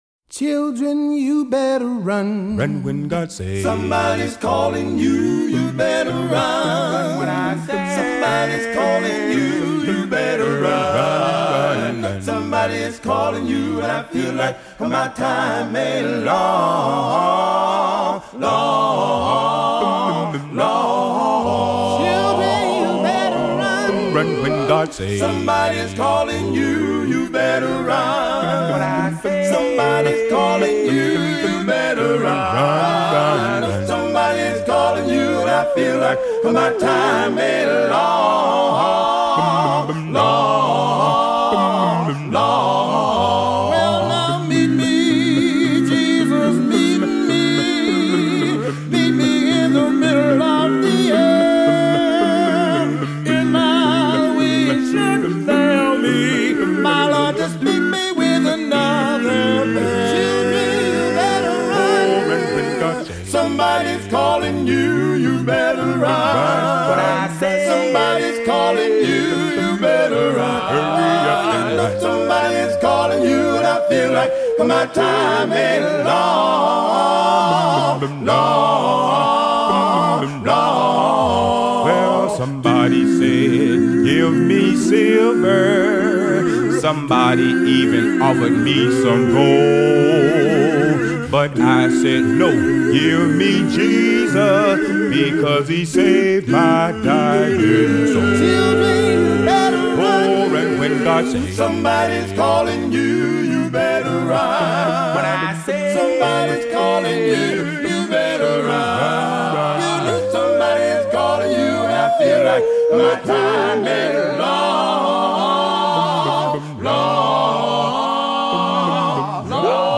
traditional spiritual